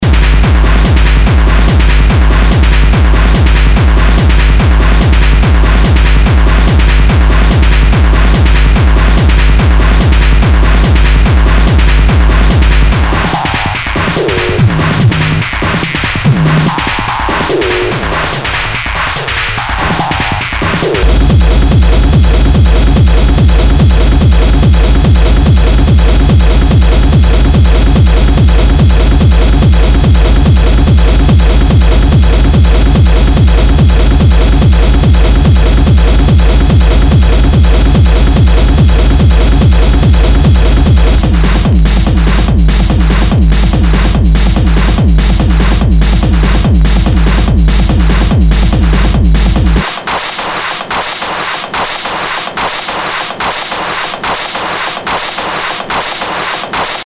HOUSE/TECHNO/ELECTRO
ナイス！ハード・テクノ！
全体にチリノイズが入ります